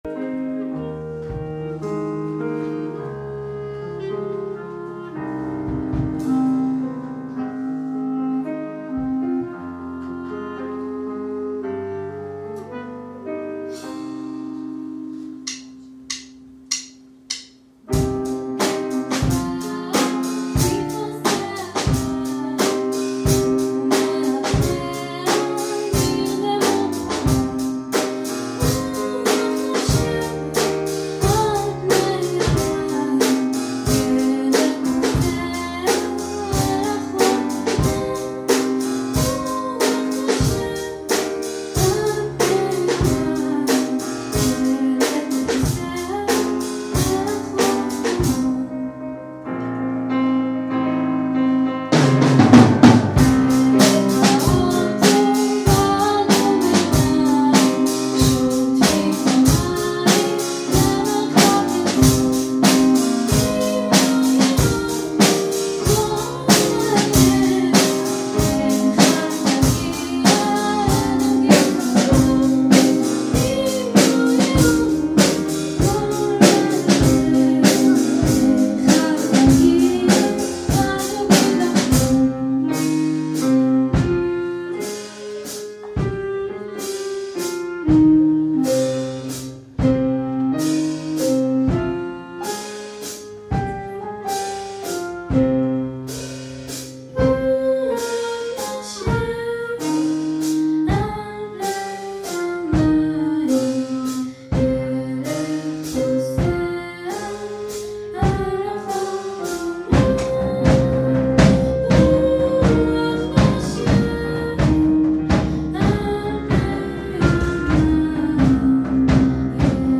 שירה, קלרינט, פסנתר, ומערכת תופים